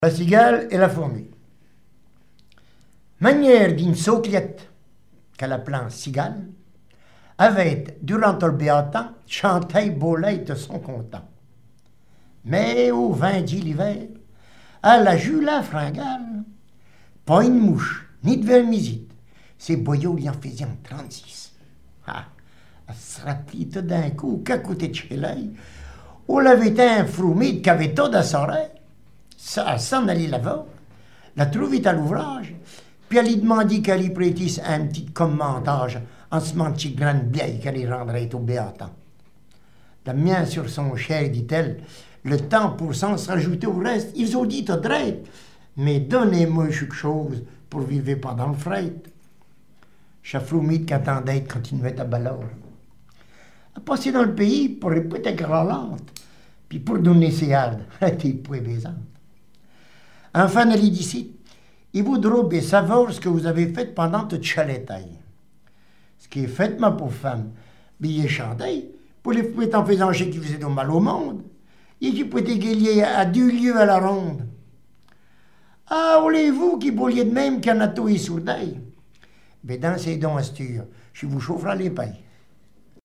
Langue Maraîchin
Genre fable
Catégorie Récit